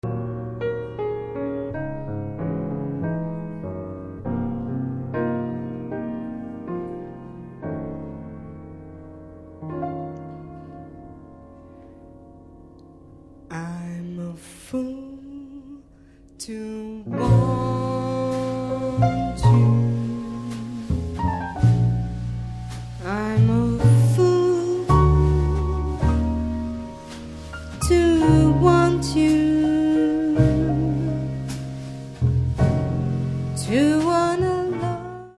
Gesang
Piano
Kontrabass
Schlagzeug